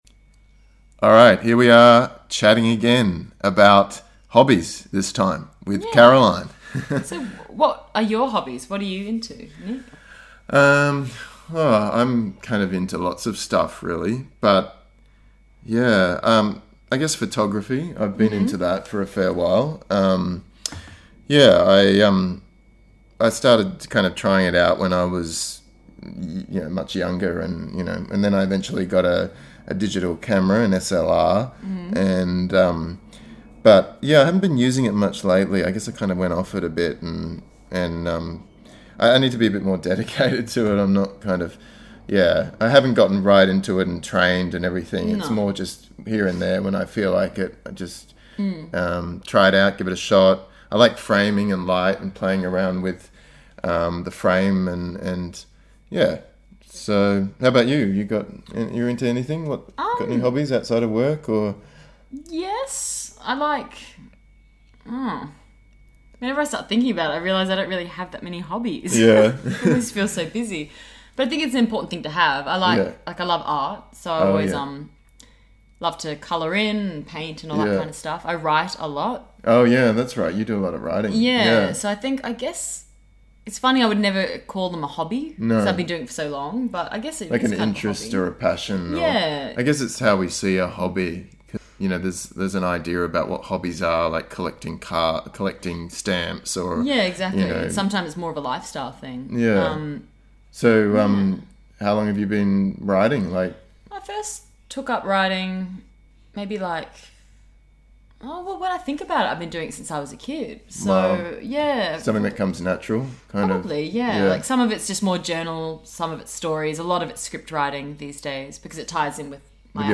A REAL CONVERSATION ANALYSIS
In this lesson we will listen to a real conversation between two native Australian English speakers.
This is a great opportunity for you to practice listening to a conversation at a normal speed and learn some of the features of native English speaking.